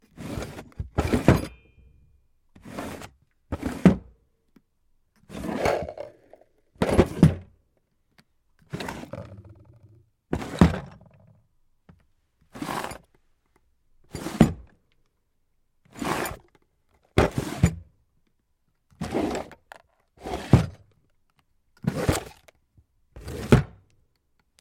随机 " 抽屉木制小零件隔间车库打开关闭各种杂物的响声
描述：抽屉木小零件cubbies车库开放关闭各种垃圾rattle.wav